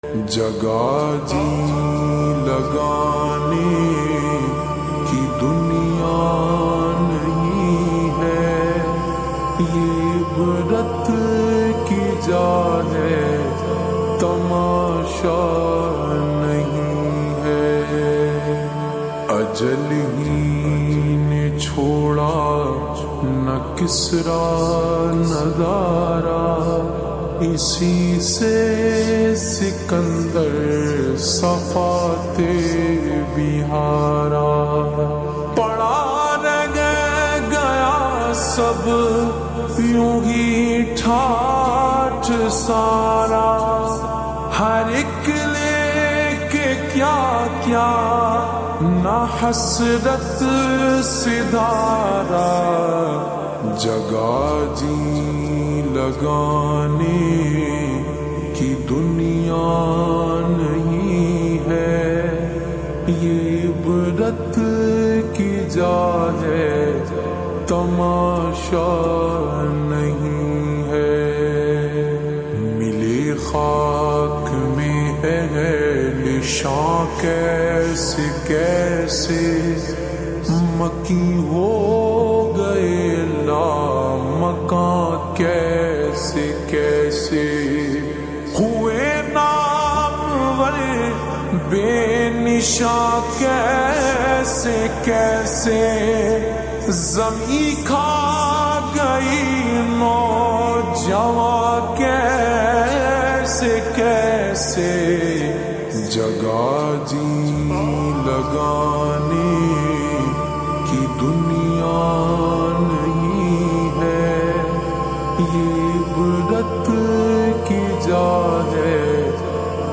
Naat
Slowed Reverb